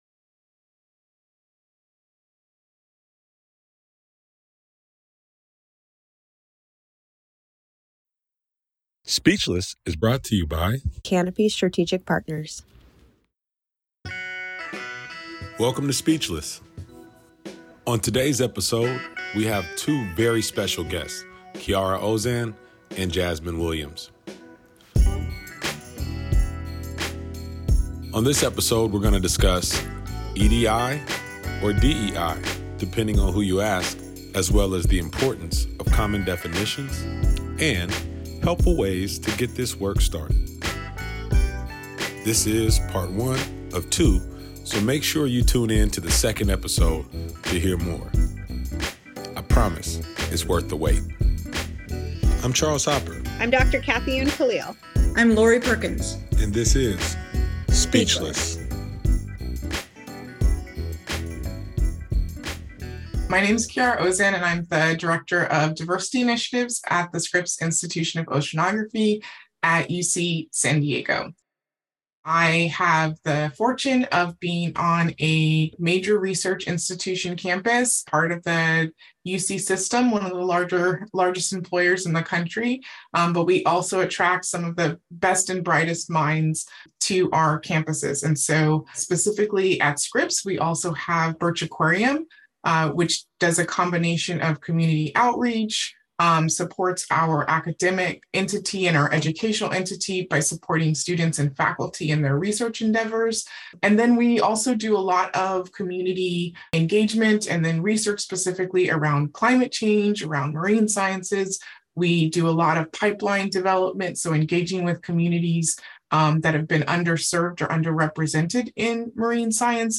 the series will feature rotating guest speakers discussing relevant issues in our zoo and aquarium organizations.